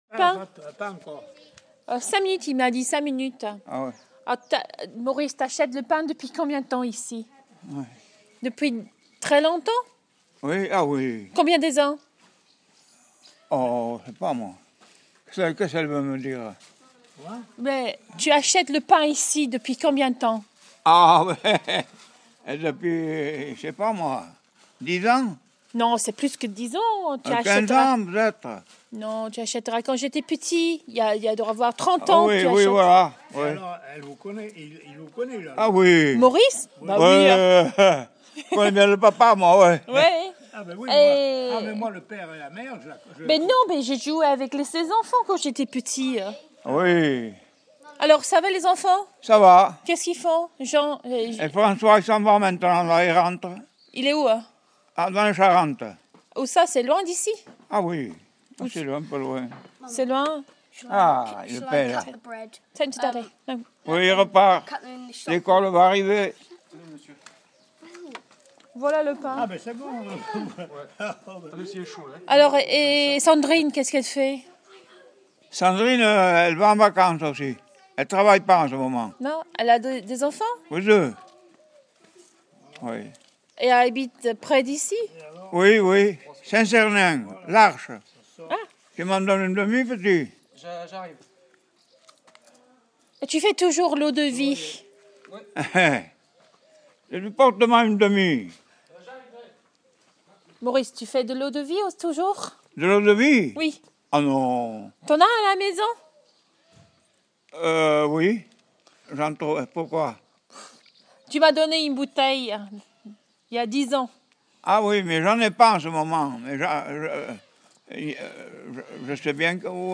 Chatting to the locals outside the bakery